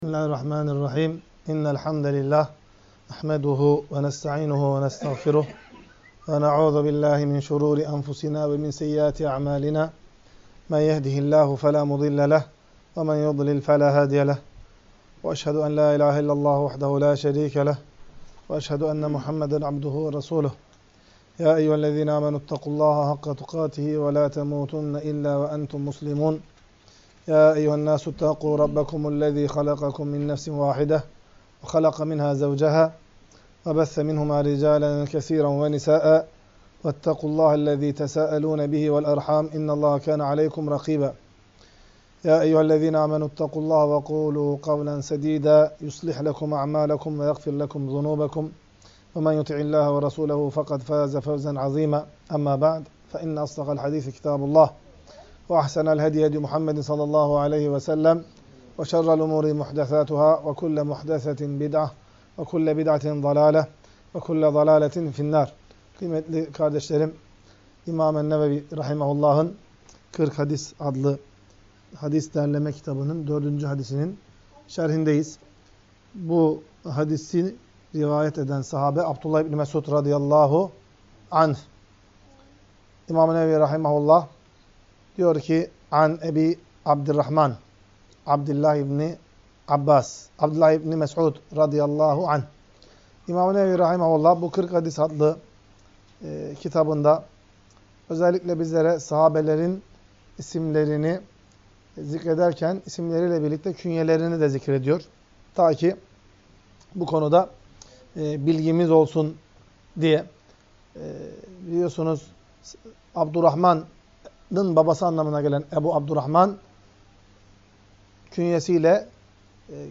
Ders - 4. Hadis